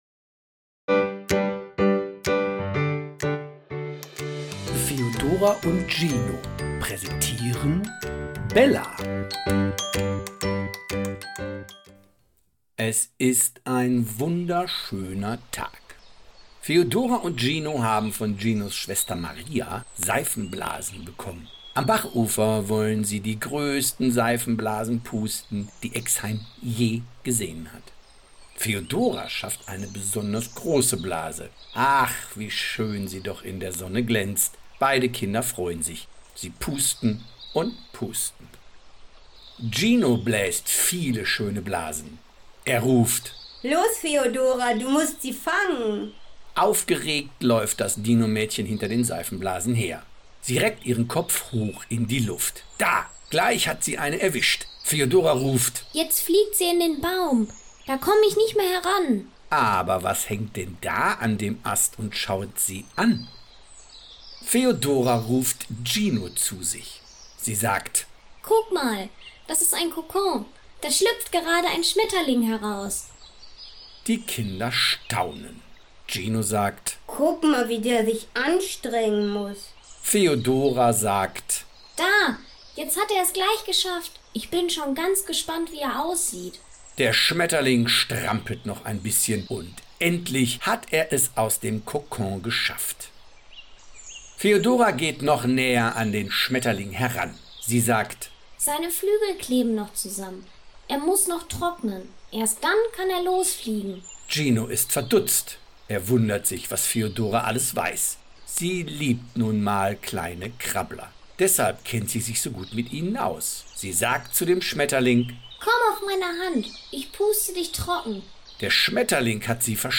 Bella – The audio play based on the popular picture book!
A lovingly narrated version full of emotion, ideal for falling asleep, relaxing, or simply listening.